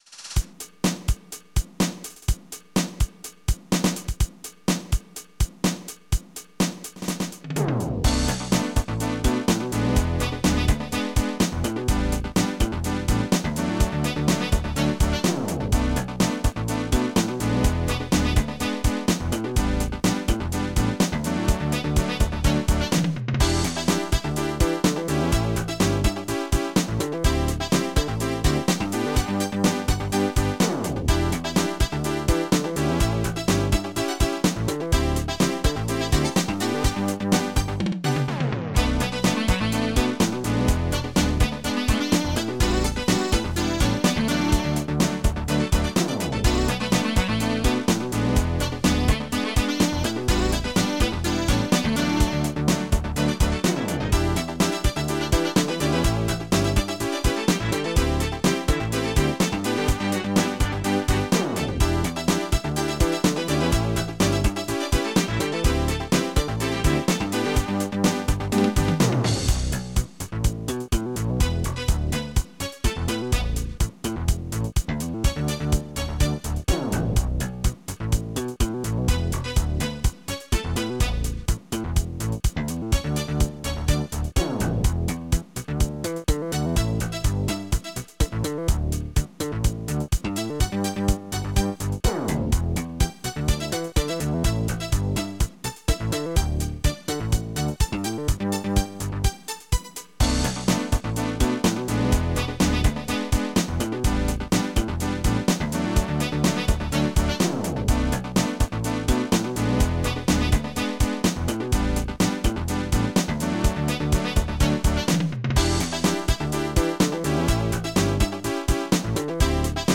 dtm (Digital Tracker)
MONO 6 voices is